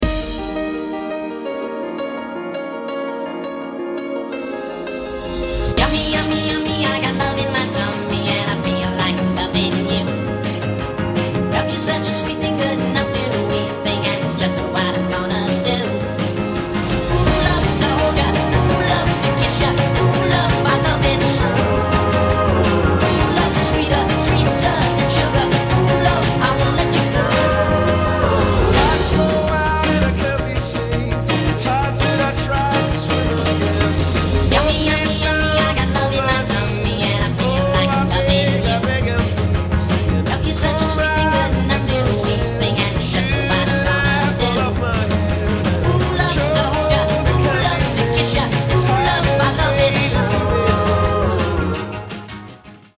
A comedy mash-up